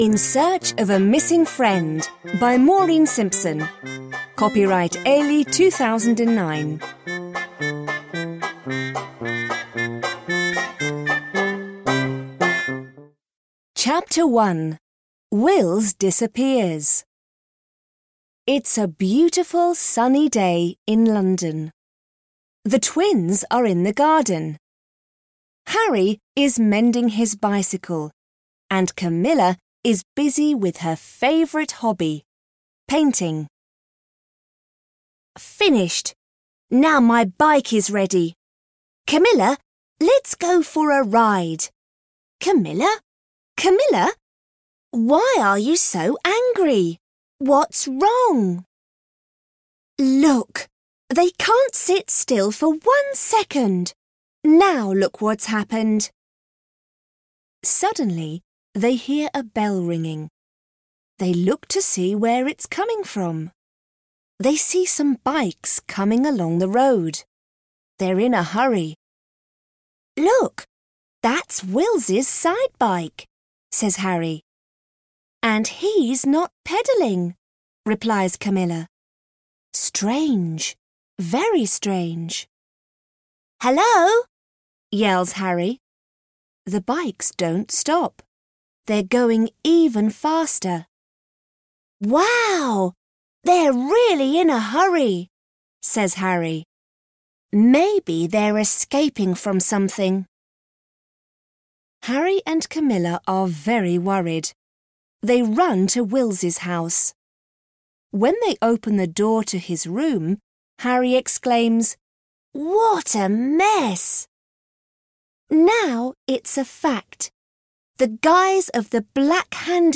Obtížnost poslechu odpovídá jazykové úrovni A1 podle Společného evropského referenčního rámce, tj. pro studenty angličtiny začátečníky.
AudioKniha ke stažení, 6 x mp3, délka 20 min., velikost 27,1 MB, česky